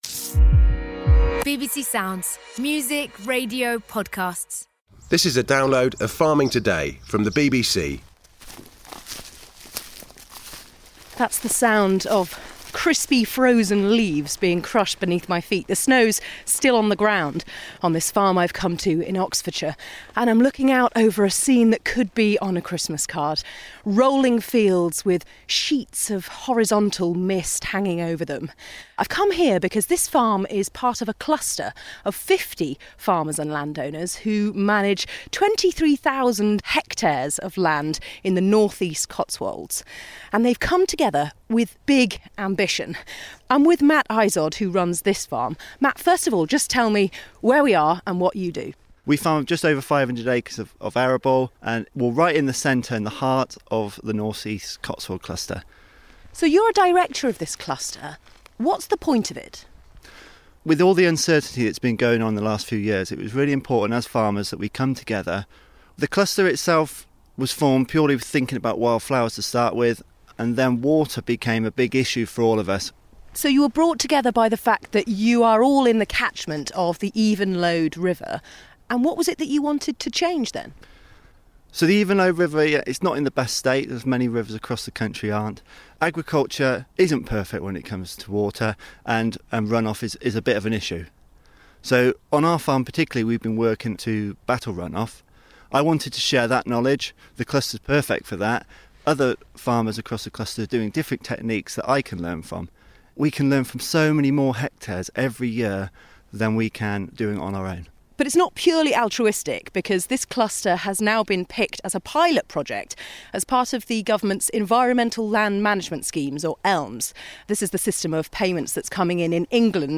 In this programme, Farming Today visited us as one of 22 pilot projects for the "Landscape Recovery" scheme. Our project group of 50 farmers and landowners are being paid to make changes across over 3,200 hectares along the Evenlode, Dorn and Glyme with the aim of improving water quality, increasing biodiversity and contributing to the prevention of urban flooding downstream.